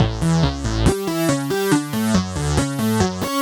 Index of /musicradar/french-house-chillout-samples/140bpm/Instruments
FHC_Arp C_140-A.wav